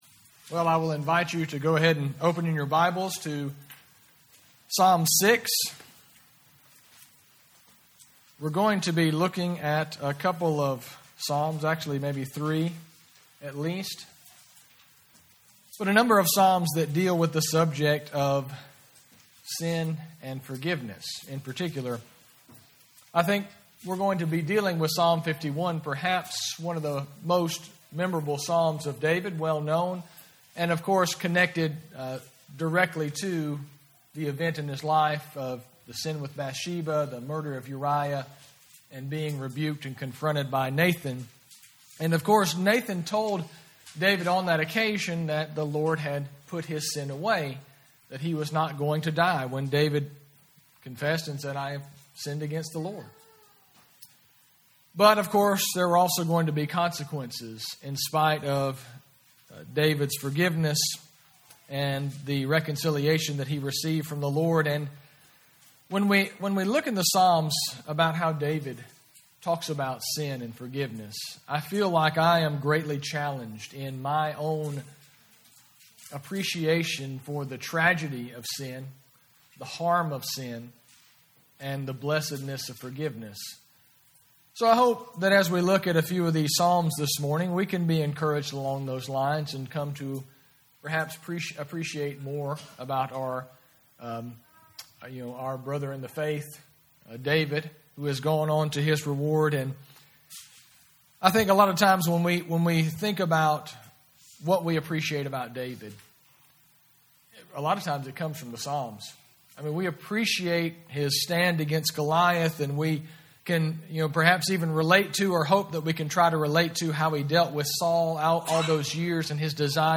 2020 Service Type: Sunday Service Preacher